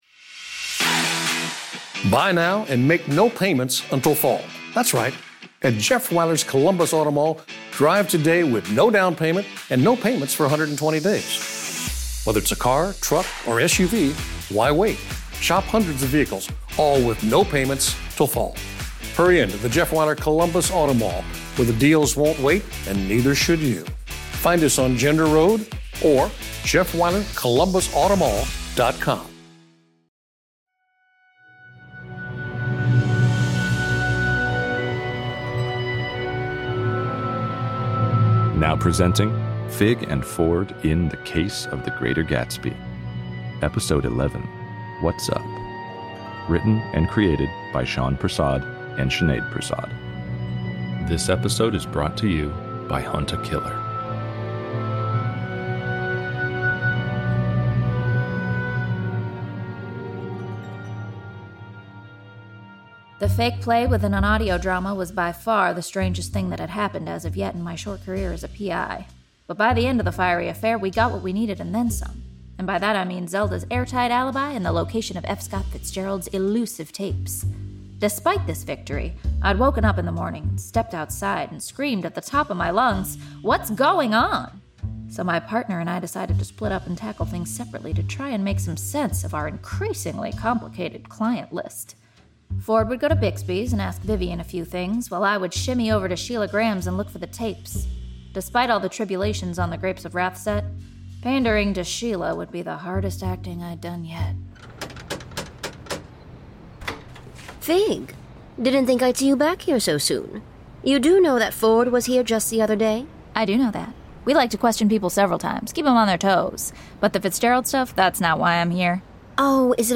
Matthew Mercer as Mo Beats